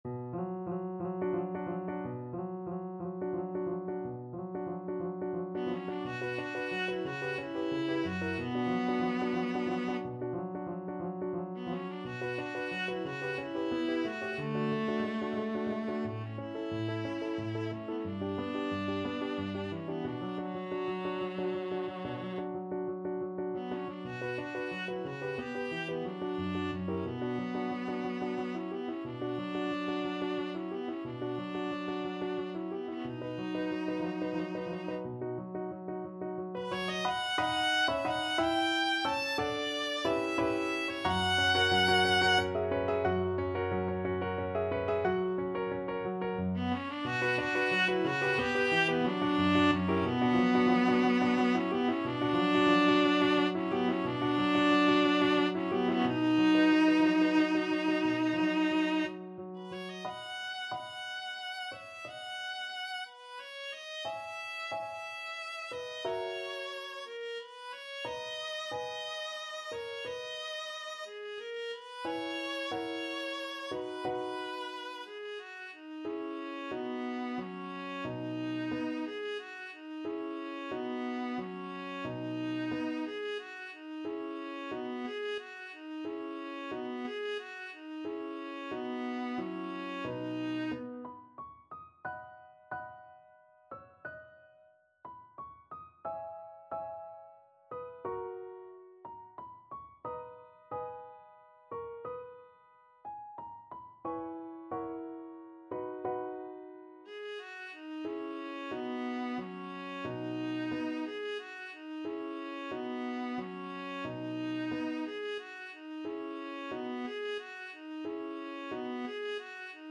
Viola
D major (Sounding Pitch) (View more D major Music for Viola )
Andante, quasi allegro =90
6/8 (View more 6/8 Music)
Classical (View more Classical Viola Music)
granados_andaluza_VLA.mp3